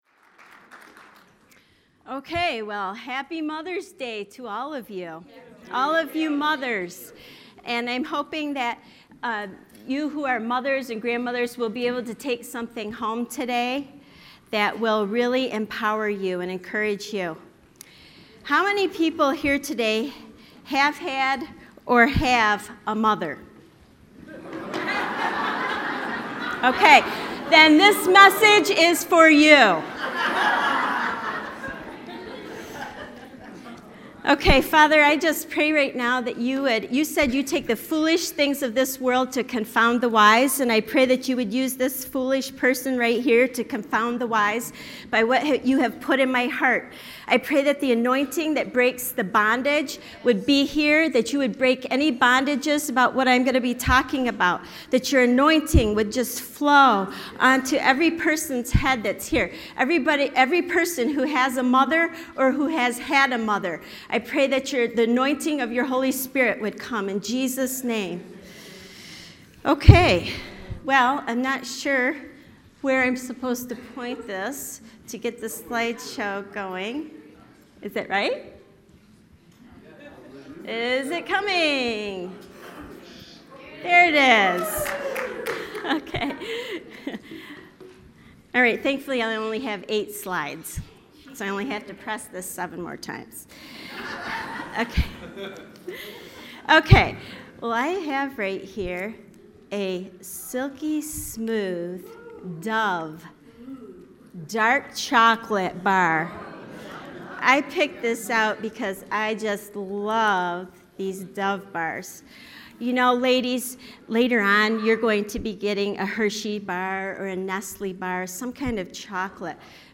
Special Mother’s Day message